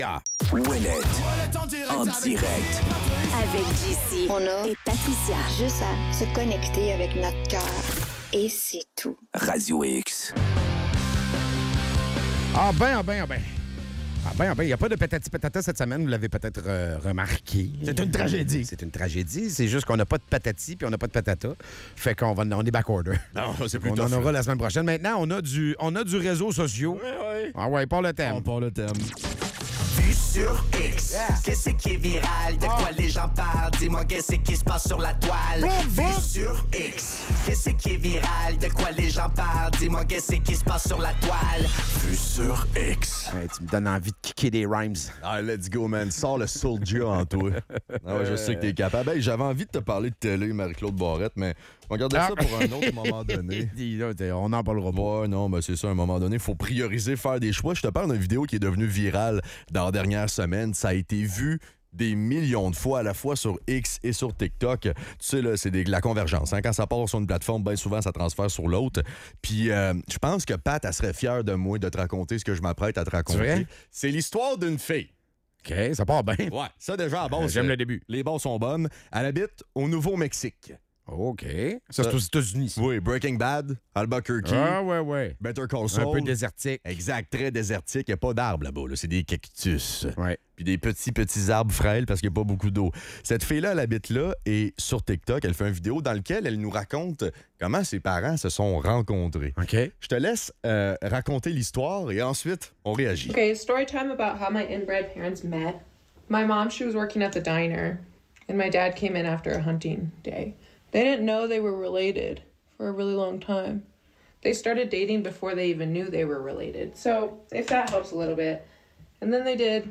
Une histoire incroyable d'amour et de consanguinité est mise en lumière avec le récit d'un couple du Nouveau-Mexique qui découvre qu'ils sont frères et sœurs après avoir déjà eu deux enfants ensemble. Les animateurs réagissent aux implications de cette révélation et abordent des questions éthiques sur les relations familiales.